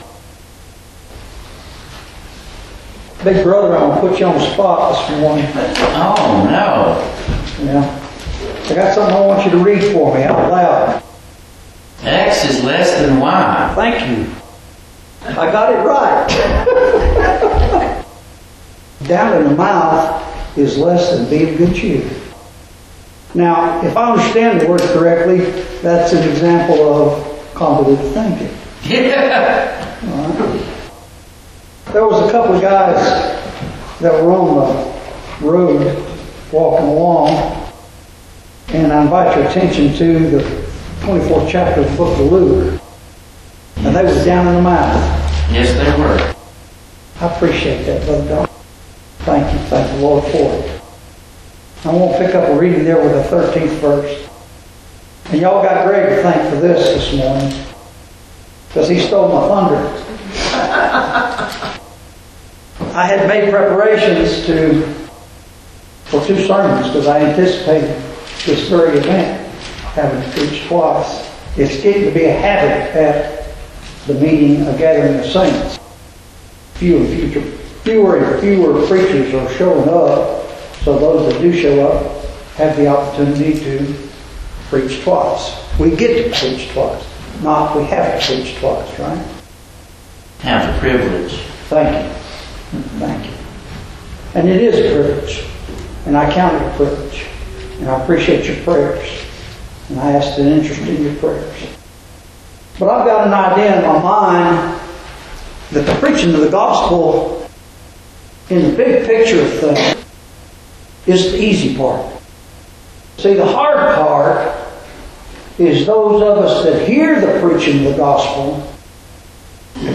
Twice a year (Spring and Fall) a meeting is held, to which all are invited, for the purpose of worshipping God and sharing fellowship with other believers. Over the span of (usually) one to two days, the congregation hears a series of sermons, usually between seven and ten in total.